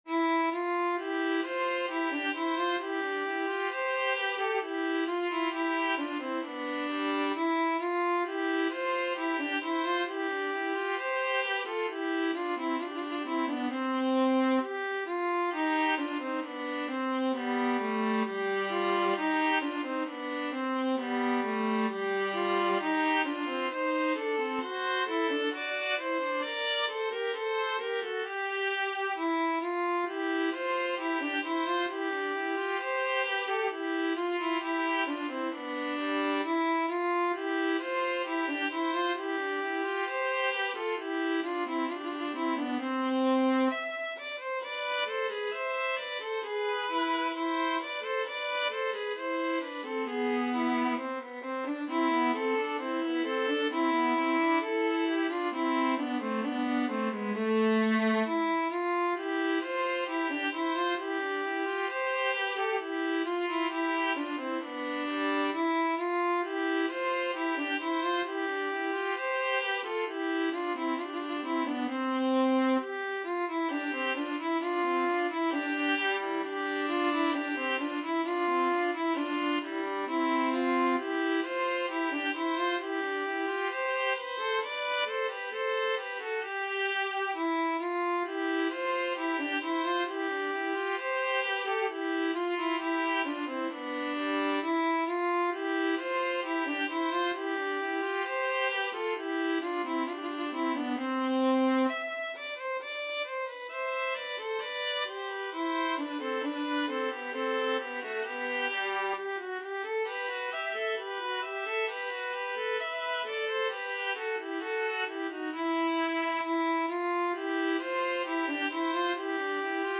2/2 (View more 2/2 Music)
~ = 100 Allegretto =c.66
Classical (View more Classical Violin-Viola Duet Music)